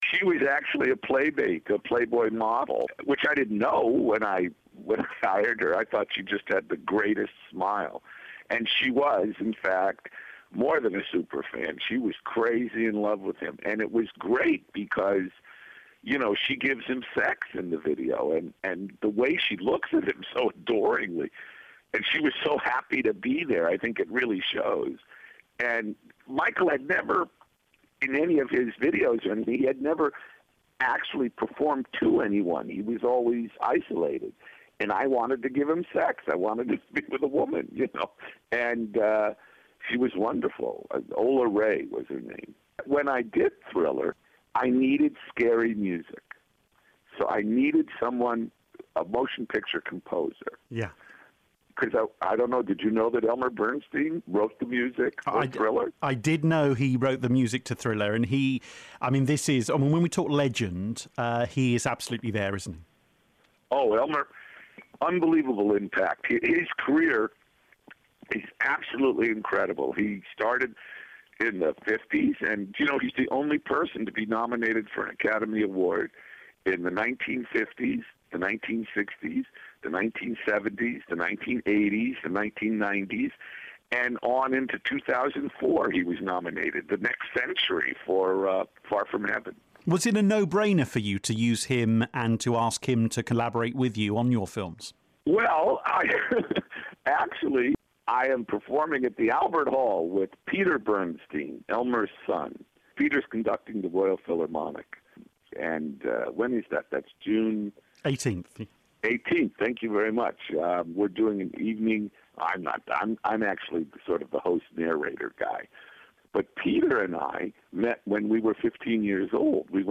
A conversation with Film Director John Landis